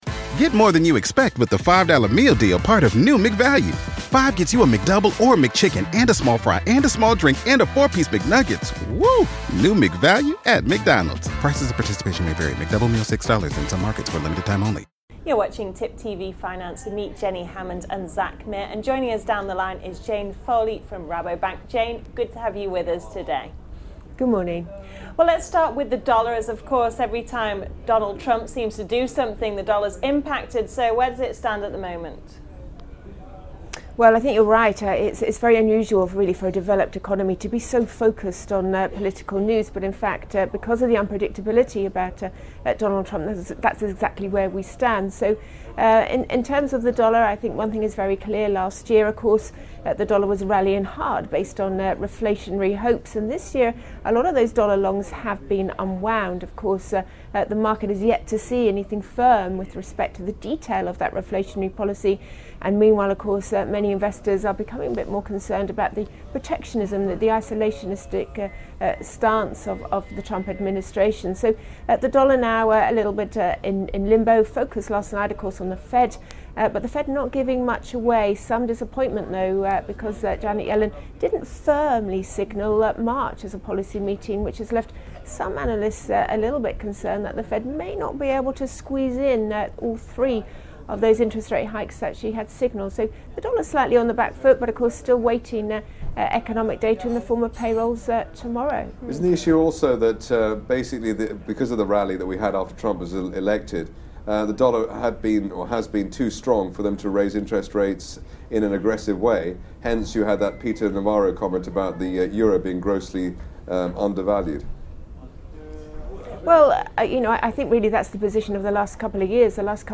In an interview with Tip TV